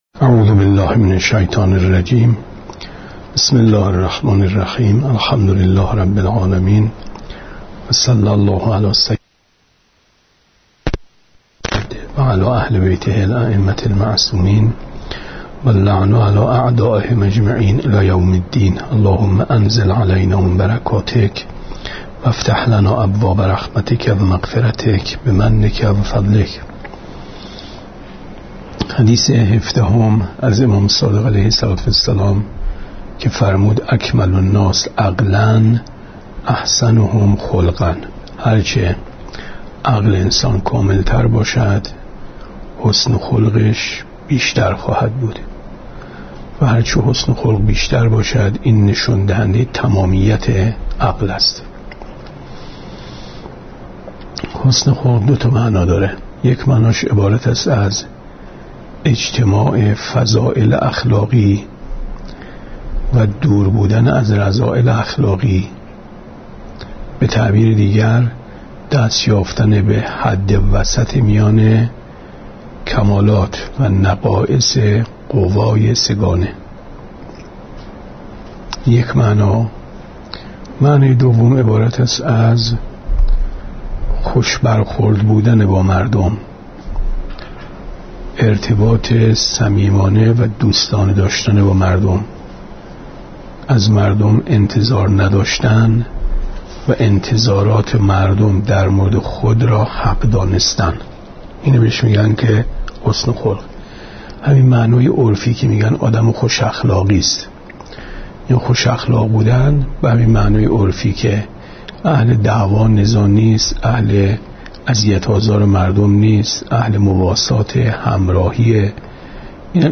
گفتارهای ماه مبارک رمضان 1436 ـ جلسه دوازدهم ـ 13/ 4/ 94 ـ شب هجدهم ماه رمضان